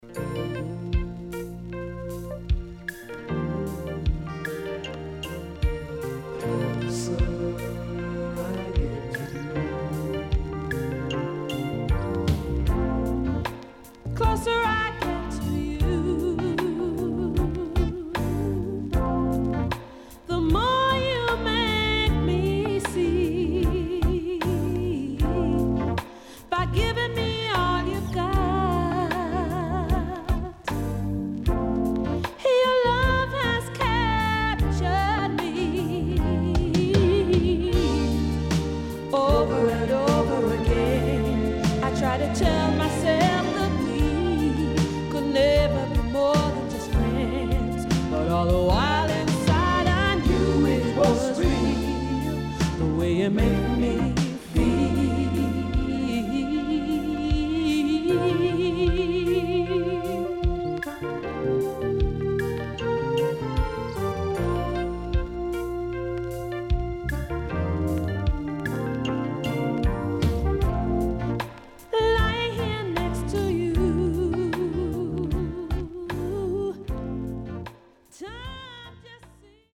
SIDE B:少しチリノイズ入りますが良好です。